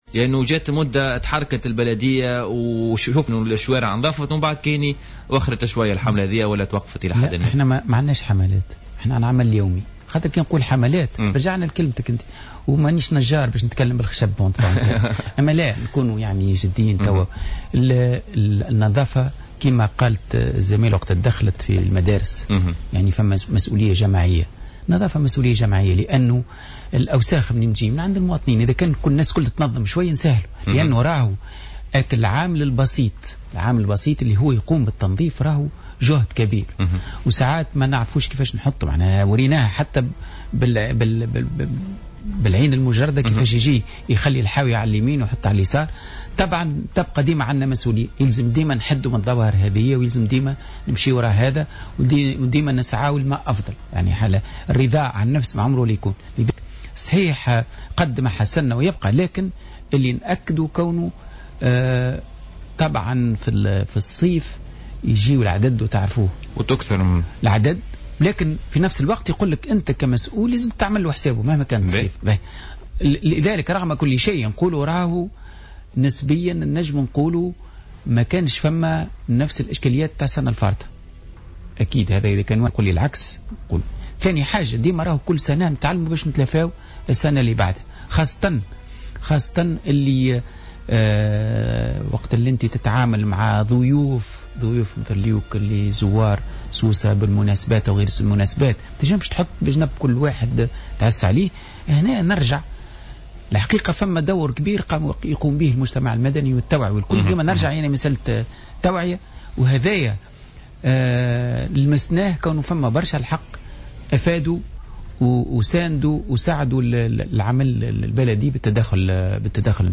كشف محمد المكني، رئيس النيابة الخصوصية بسوسة اليوم الثلاثاء على "الجوهرة أف أم" في برنامج بوليتيكا أنه تم تسجيل سرقة حاويتين ضخمتين للقمامة في مدينة سوسة العام الماضي.